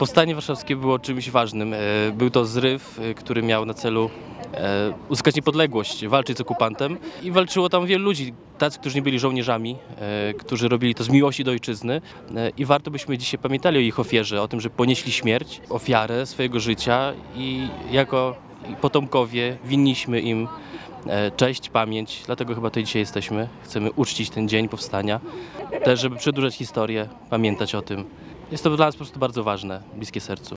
Na uroczystości nie mogło zabraknąć licznej grupy ełckich harcerzy.
harcerz-powstanie.mp3